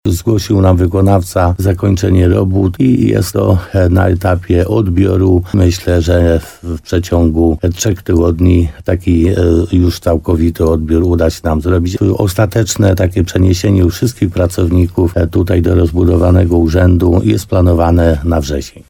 – Teraz czekamy na odbiory techniczne – mówi Piotr Stach, wójt gminy Laskowa.